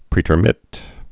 (prētər-mĭt)